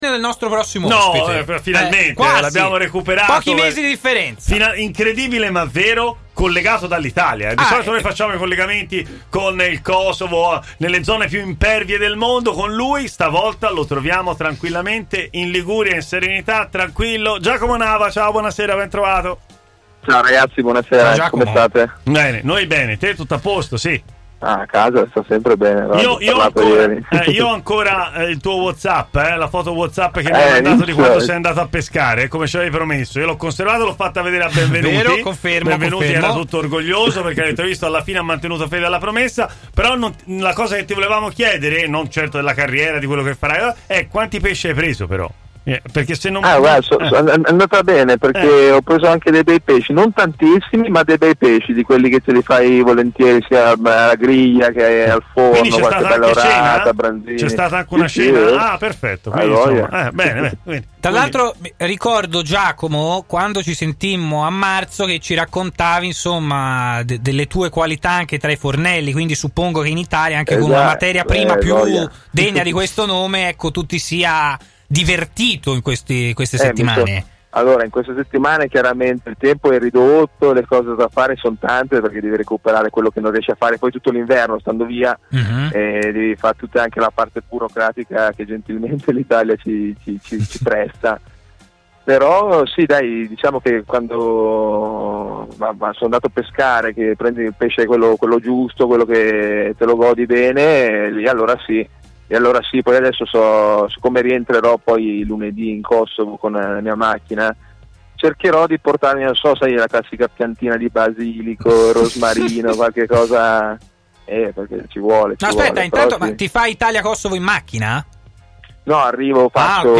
si è collegato in diretta a TMW Radio, intervenendo nel corso della trasmissione Stadio Aperto